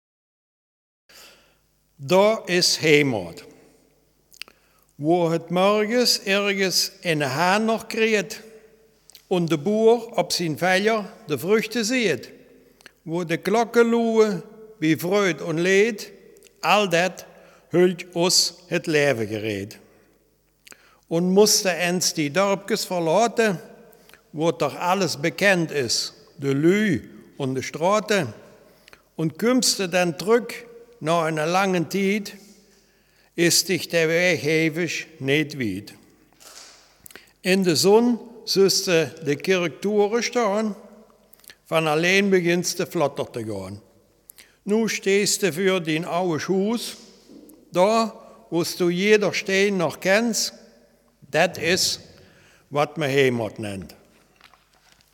Selfkant-Platt
Geschichte